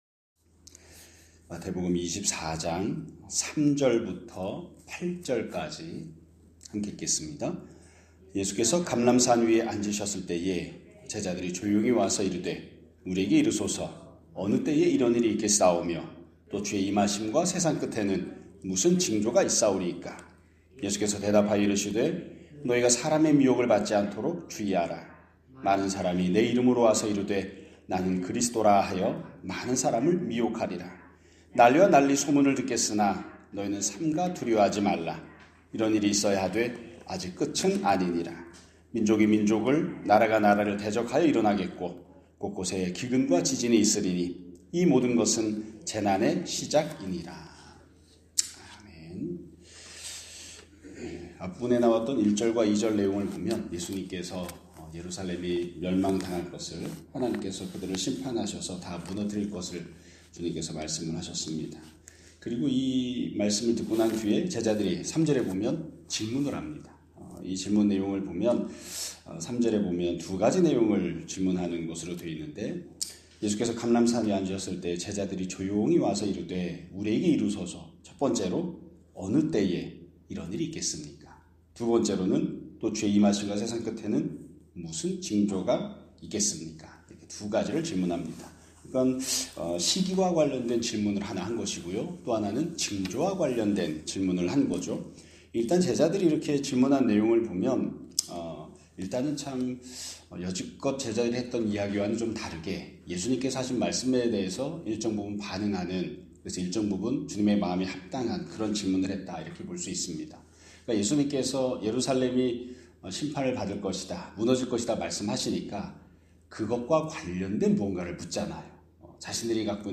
2026년 3월 9일 (월요일) <아침예배> 설교입니다.